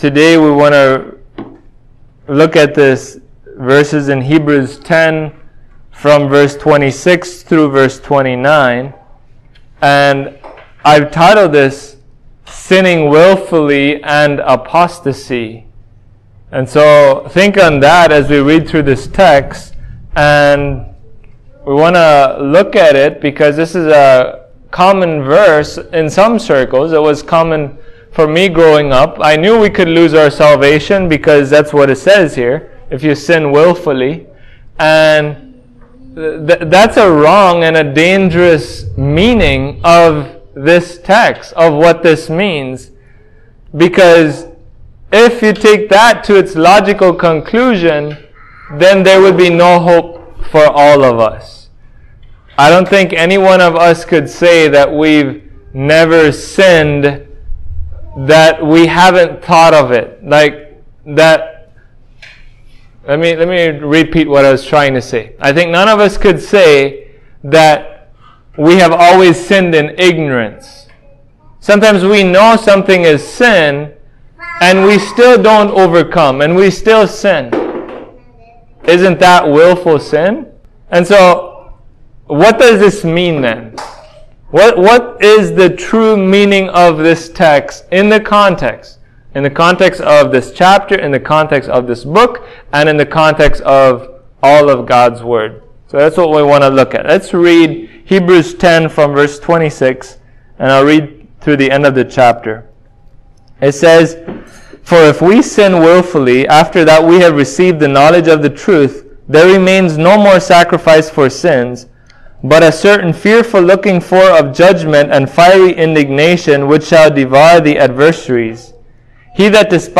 Hebrews 10:26-39 Service Type: Sunday Morning Can a Christian lose his or her salvation?